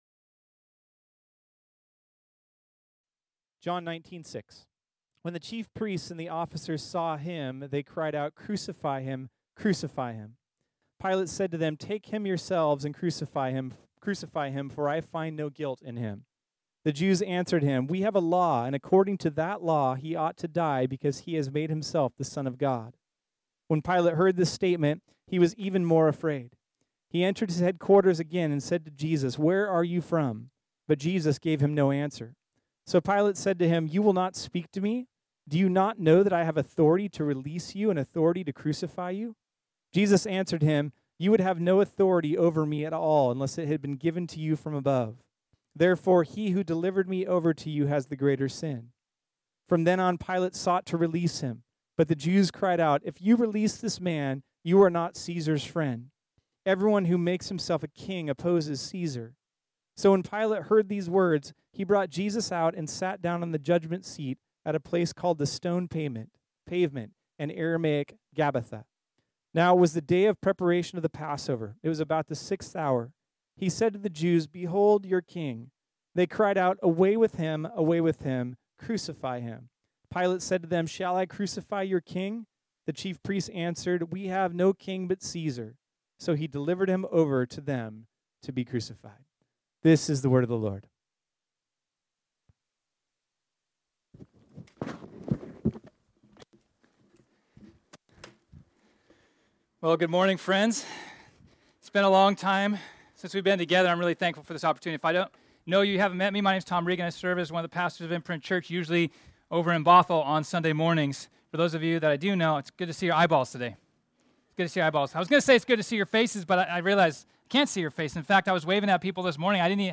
This sermon was originally preached on Sunday, July 26, 2020.